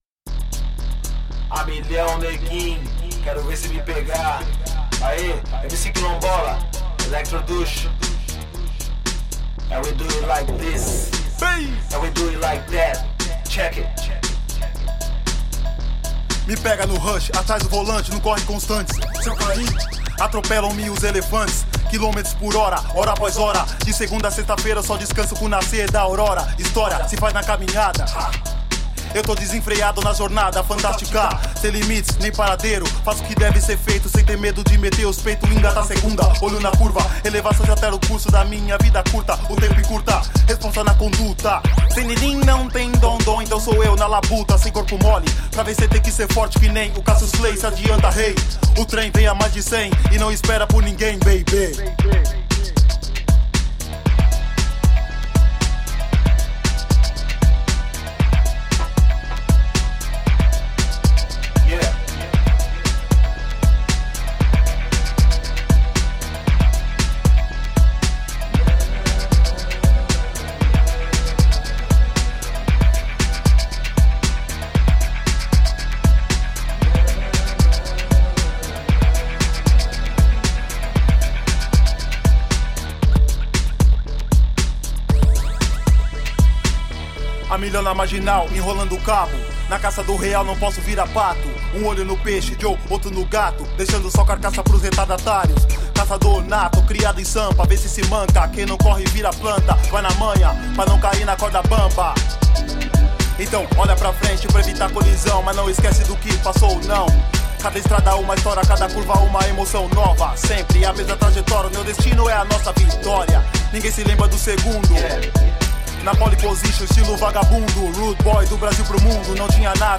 Funky urban tropical electro trip-hop from brazil.
Tagged as: Hip Hop, Other